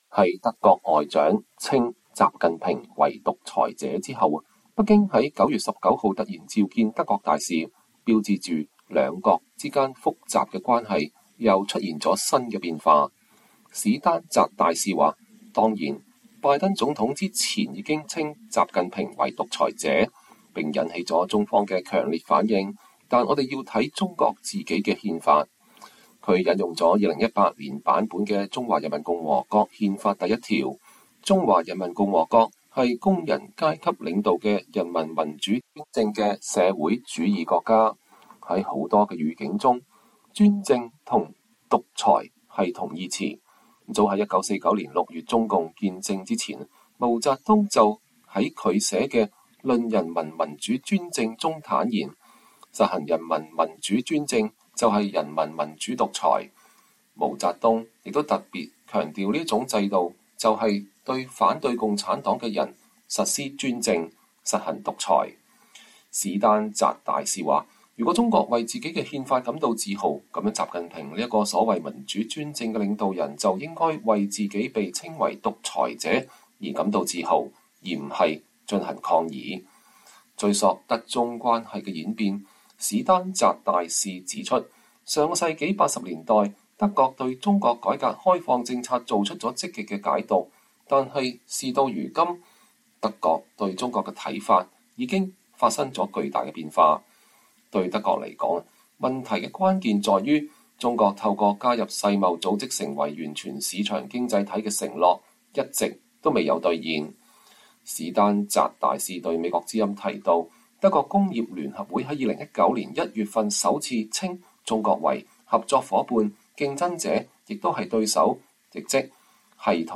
VOA專訪前德國駐中國大使史丹澤: 德中關係: 從夥伴到競爭與對抗